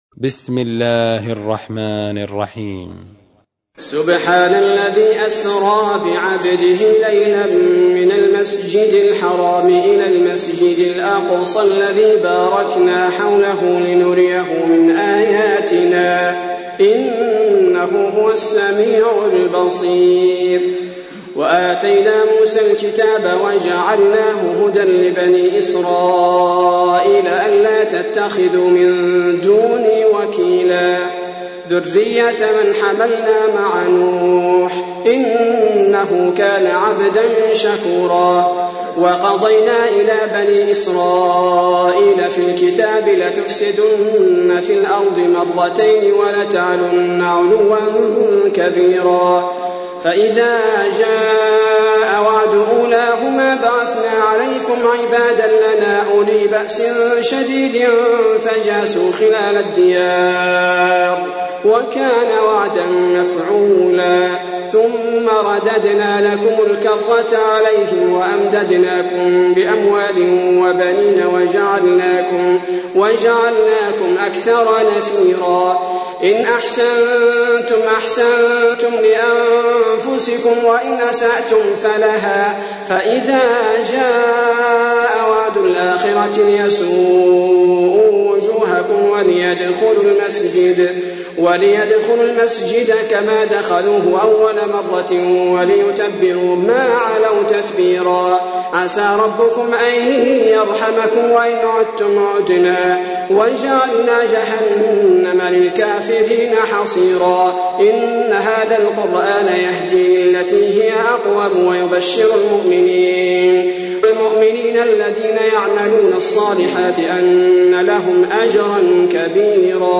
تحميل : 17. سورة الإسراء / القارئ عبد البارئ الثبيتي / القرآن الكريم / موقع يا حسين